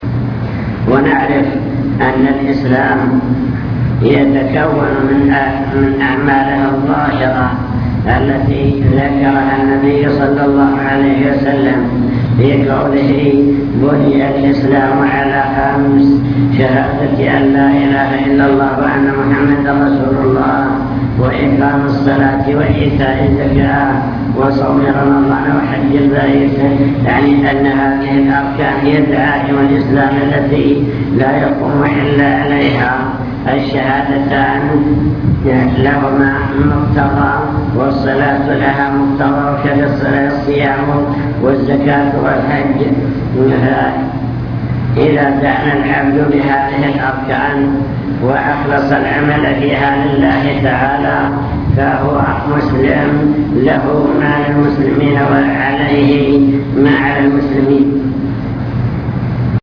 المكتبة الصوتية  تسجيلات - محاضرات ودروس  أطب مطعمك